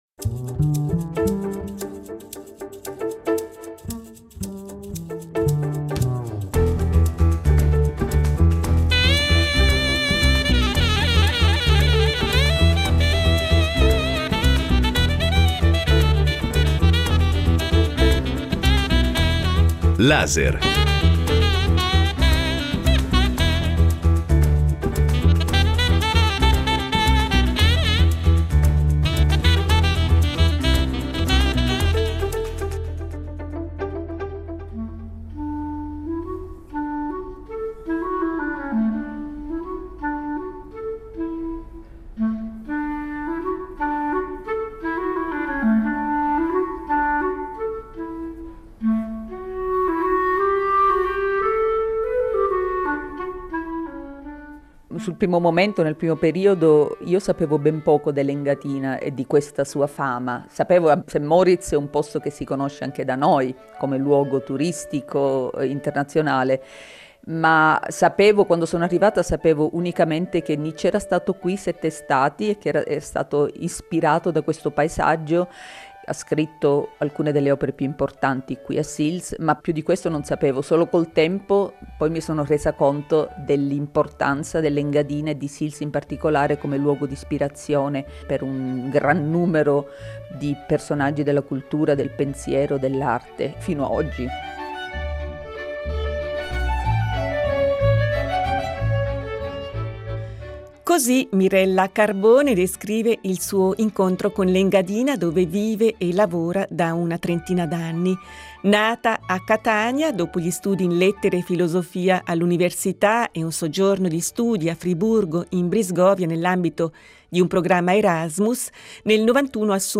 L’abbiamo incontrata a Sils Maria.